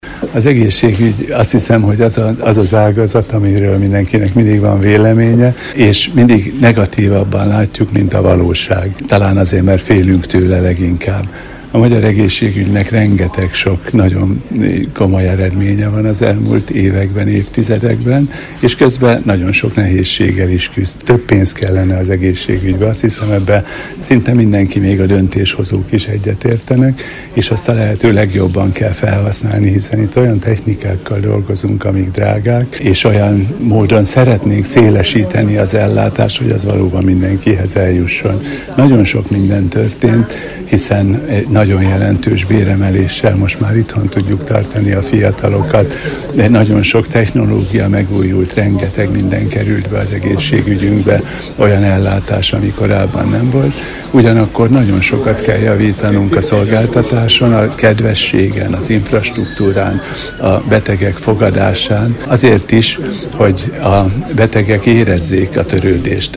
A Paksi Konzervatív Egyesület és a Keresztény Értelmiségiek Szövetségének Paksi Csoportja közös szervezésű előadás sorozatában ezúttal a sokat szidott magyar egészségügy került terítékre.